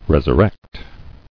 [res·ur·rect]